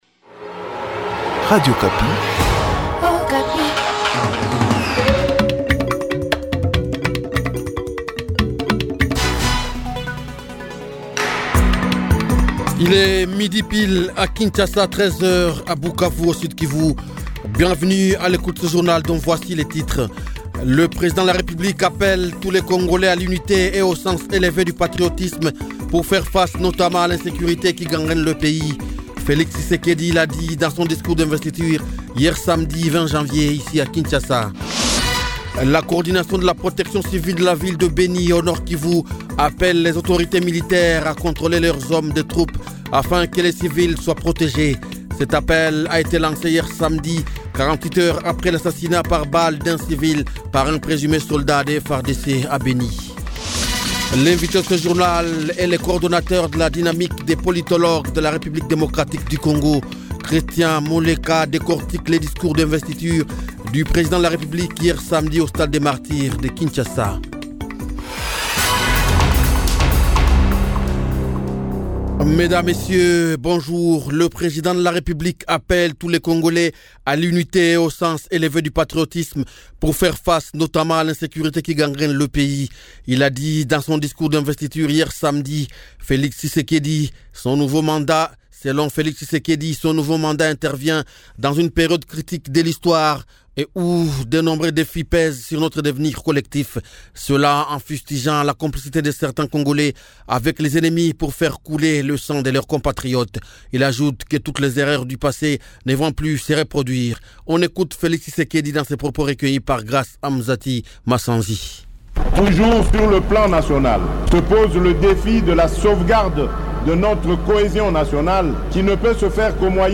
Edition de Dimanche, le 21 Janvier 2024 Midi 12 heures Kinshasa : L’appel de Felix Tshisekedi appelle à l’unité pour faire face au défi sécuritaire Goma : Attentes de la population de Goma. Vox-pop Kindu : Attentes l'investiture du chef de l'État.
Edition de Dimanche, le 21 Janvier 2024 Midi 12 heures